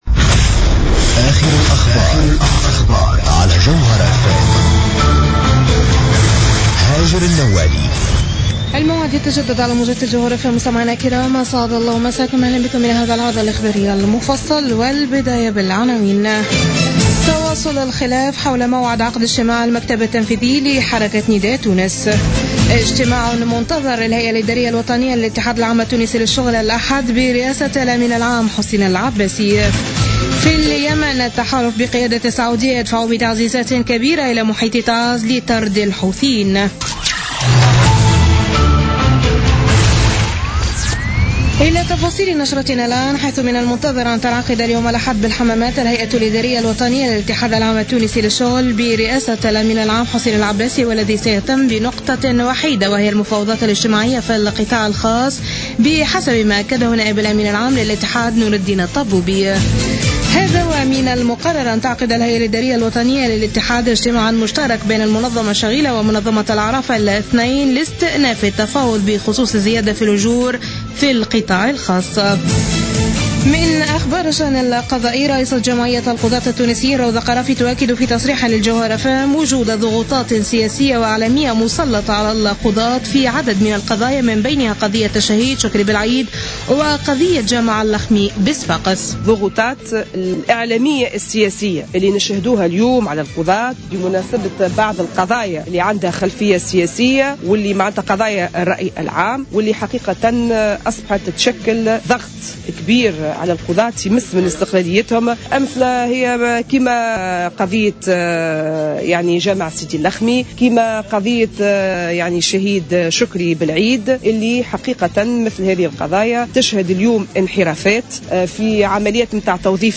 نشرة أخبار منتصف الليل ليوم اللأحد 08 نوفمبر 2015